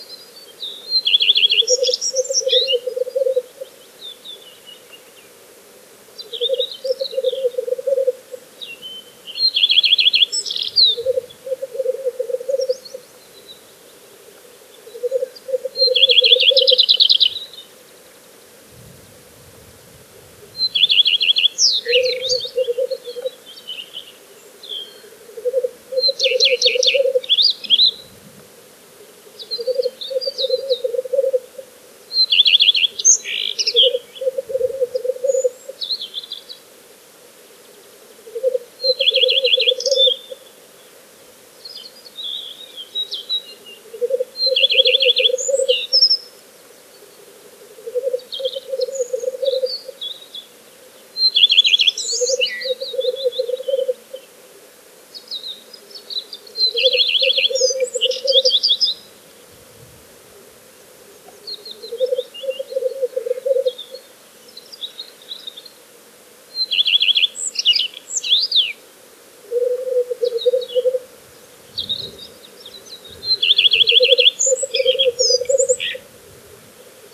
тетерев, Lyrurus tetrix
СтатусТерриториальное поведение